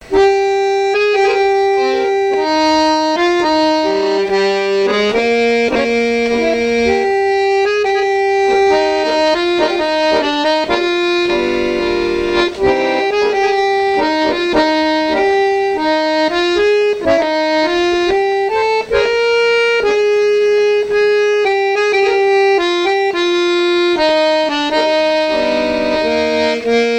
danse : tango
Pièce musicale inédite